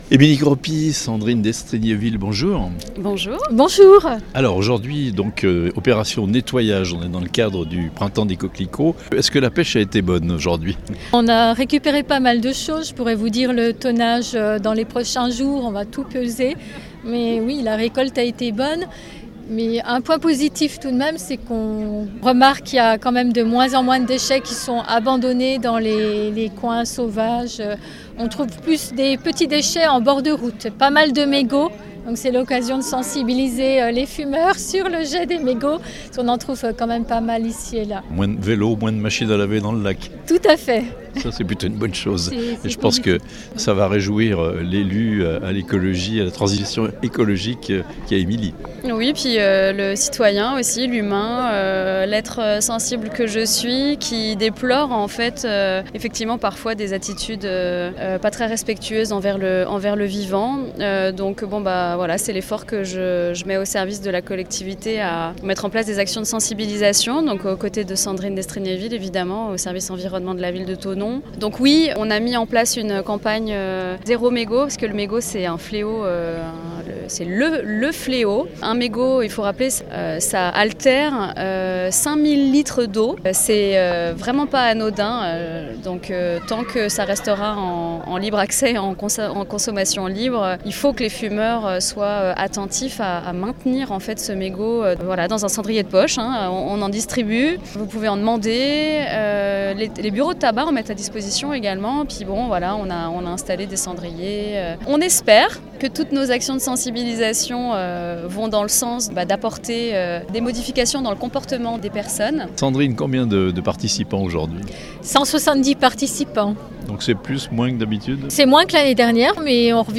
Thonon : une opération "nettoyage de la ville" couronnée de succès (interviews)